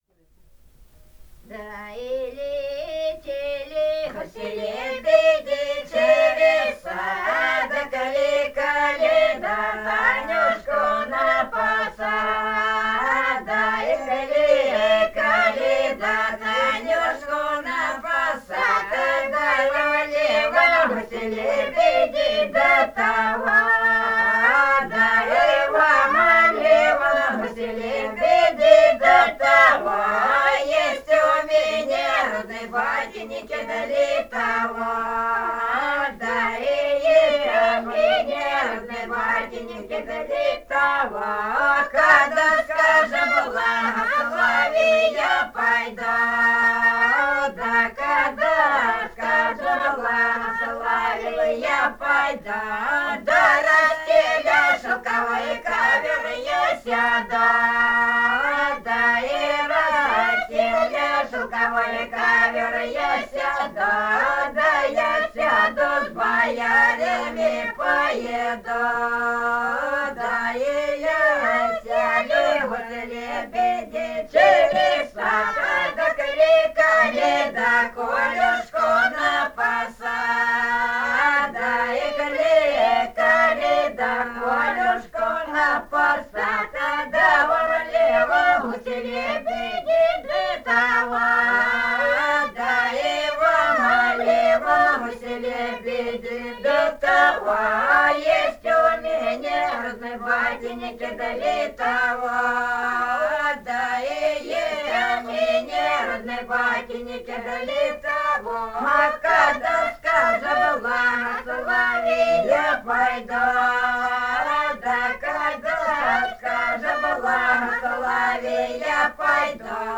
Этномузыкологические исследования и полевые материалы
Ростовская область, г. Белая Калитва, 1966 г. И0941-08